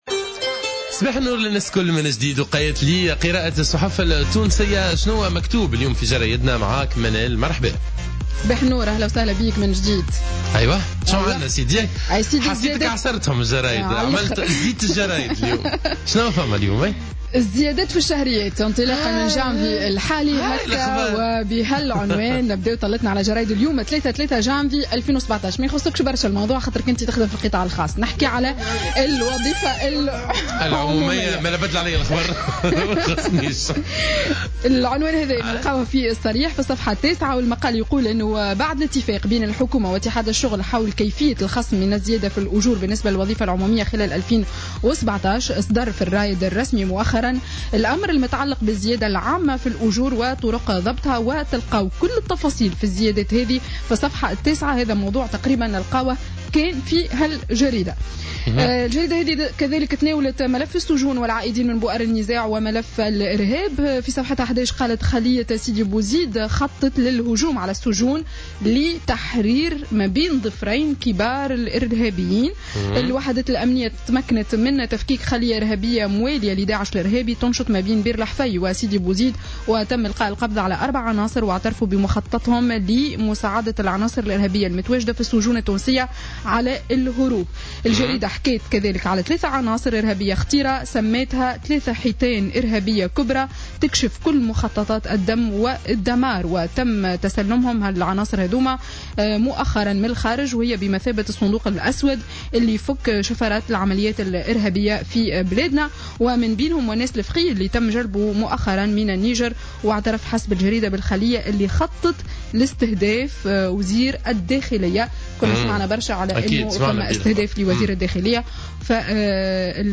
Revue de presse 03/01/2017 à 09:18